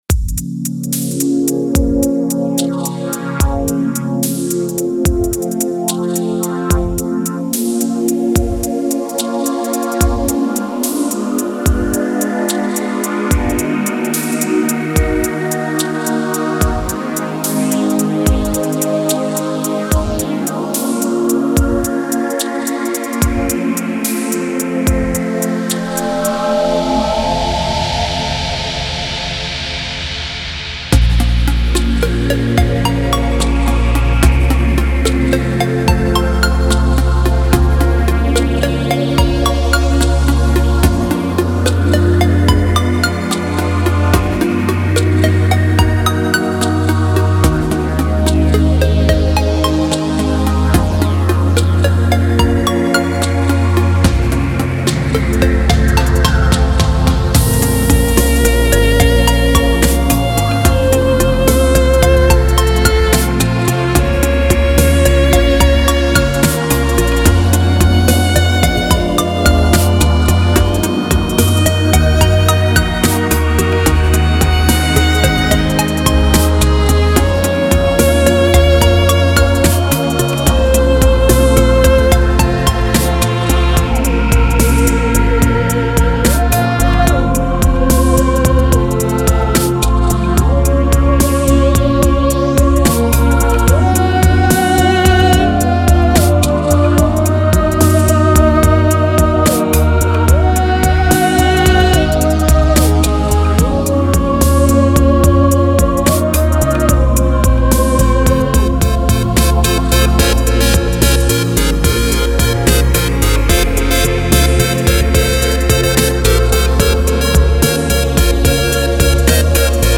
Genre: Space.